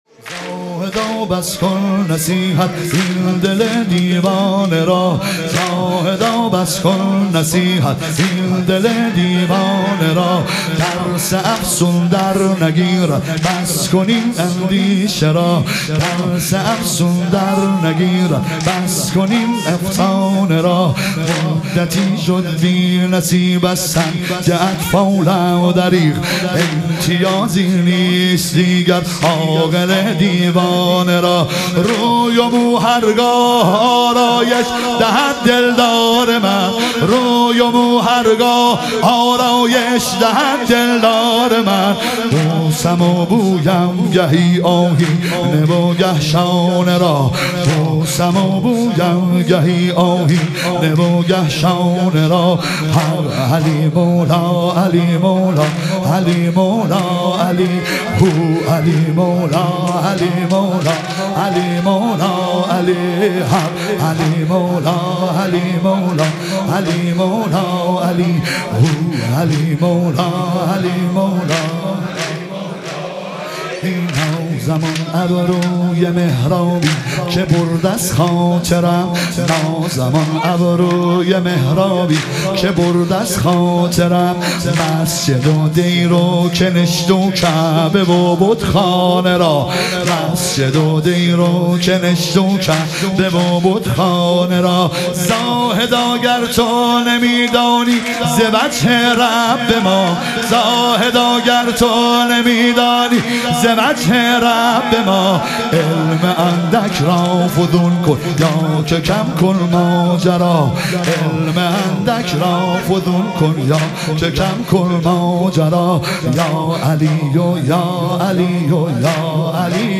لیالی قدر و شهادت امیرالمومنین علیه السلام - واحد